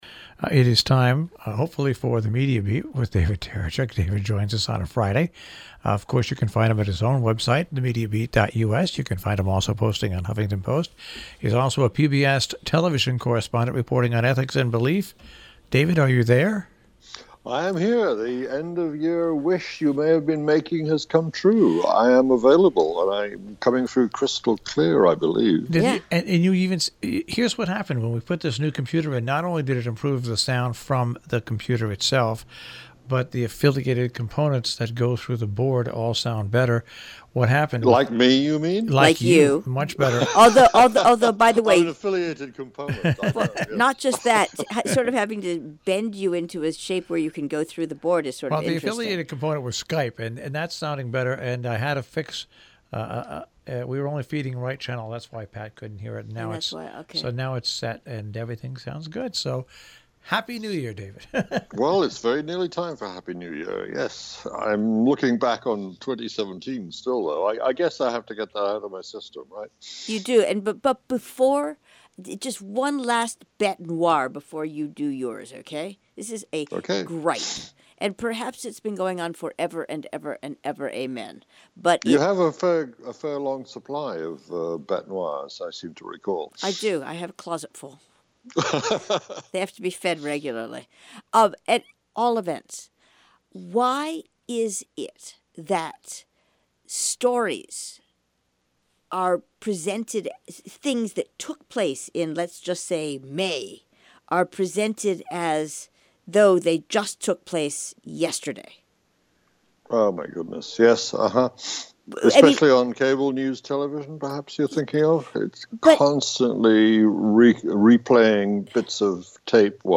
A radio version of THE MEDIA BEAT appears every week on the NPR Connecticut station WHDD – live on Friday morning and rebroadcast over the weekend.